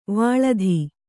♪ vāḷadhi